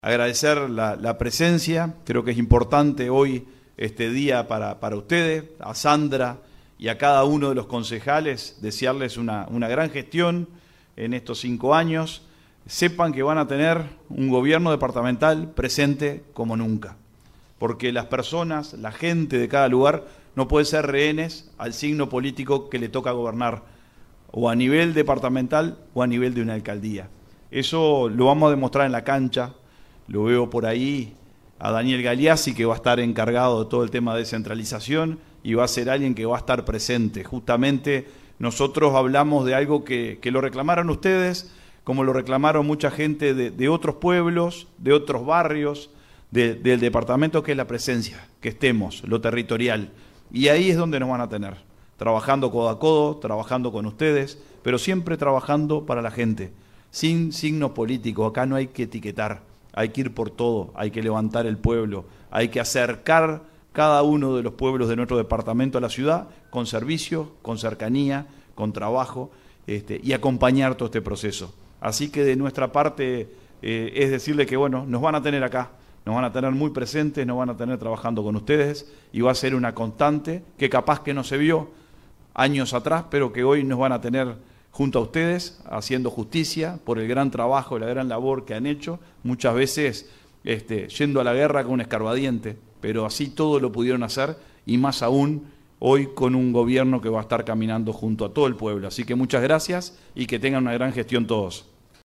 El cierre del acto estuvo a cargo del intendente de Salto, Dr. Carlos Albisu, quien brindó un mensaje de respaldo institucional tanto al Municipio de San Antonio como a todos los municipios del departamento.